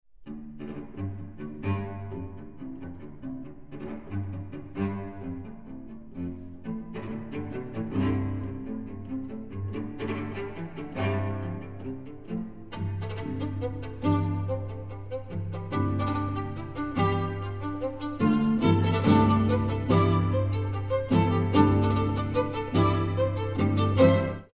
Super Audio CD